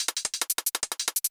• 12 HH Loops: Enhance your beats with high-quality hi-hat loops that bring crispness and precision to your drum patterns.